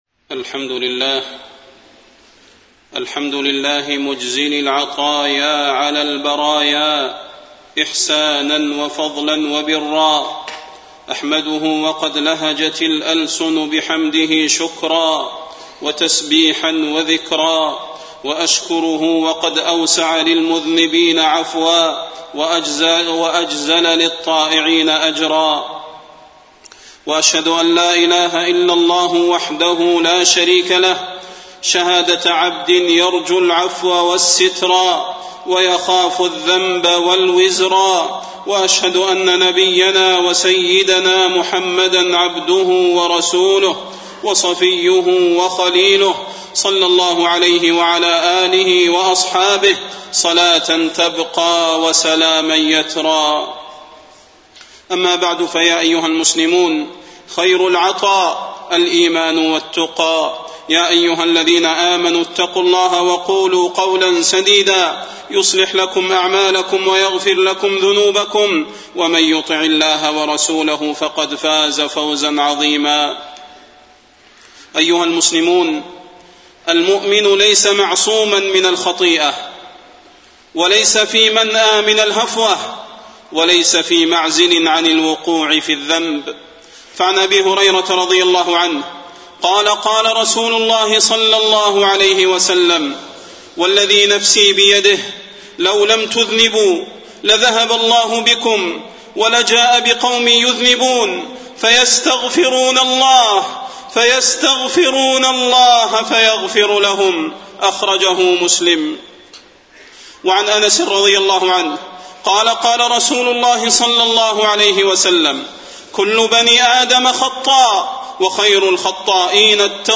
تاريخ النشر ٤ جمادى الأولى ١٤٣٢ هـ المكان: المسجد النبوي الشيخ: فضيلة الشيخ د. صلاح بن محمد البدير فضيلة الشيخ د. صلاح بن محمد البدير حان وقت التوبة